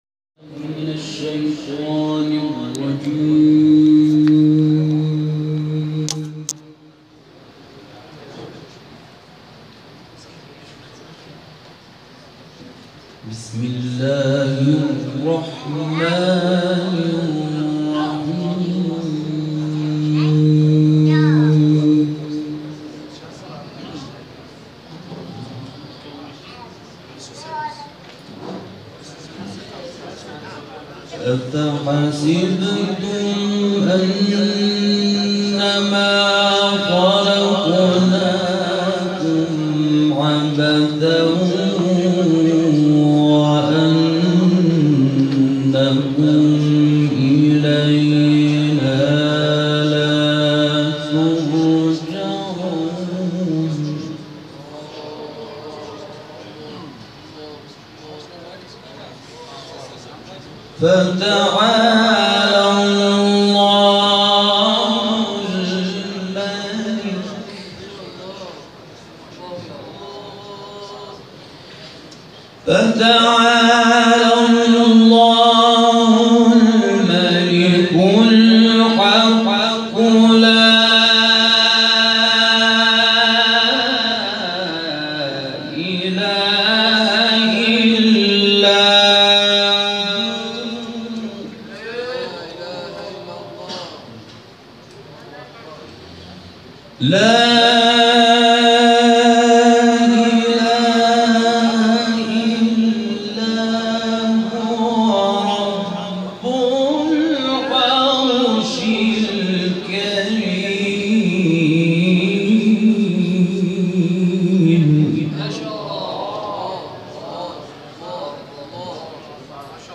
گروه فعالیت‌های قرآنی: محفل انس باقرآن کریم روز گذشته، نهم تیرماه در تالار پردیس ابن‌بابویه برگزار شد.